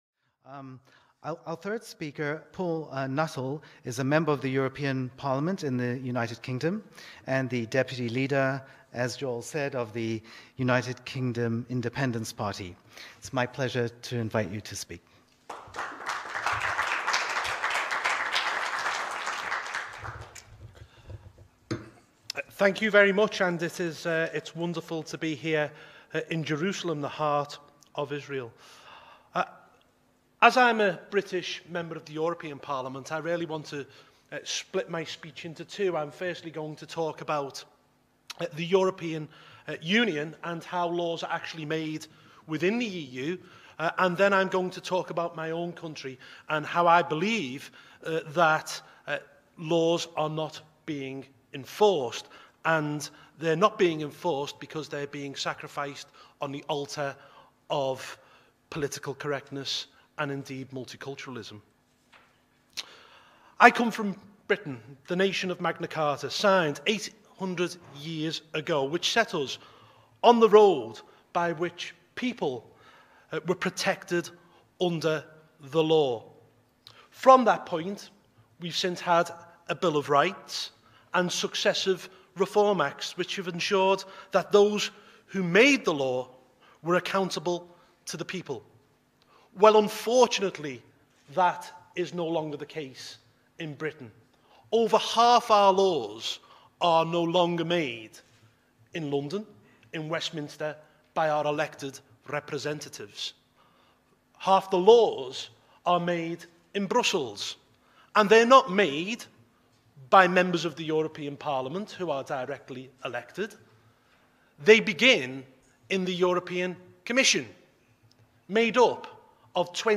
From the UKIP MEP’s Youtube channel – UKIP Deputy Leader Paul Nuttall speaks at the Jerusalem Leaders Summit (2 speeches)
PaulNuttallJerusalemLeadersSummit.mp3